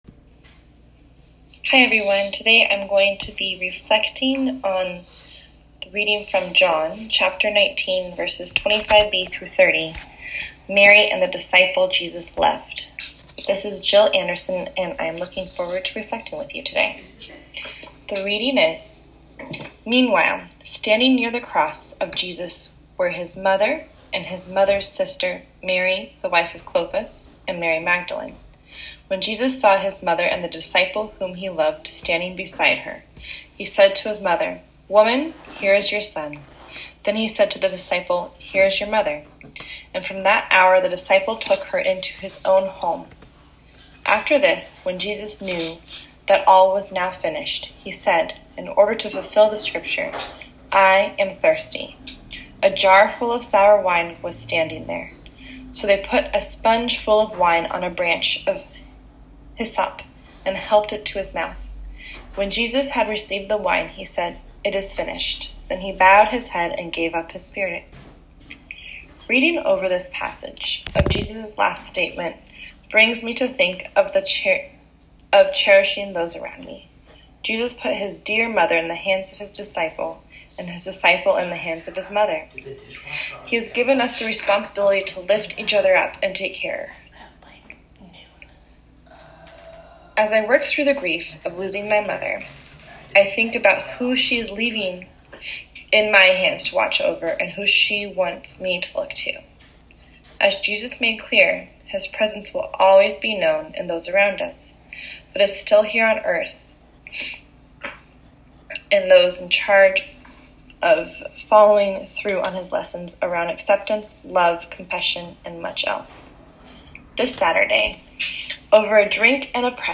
A Reflection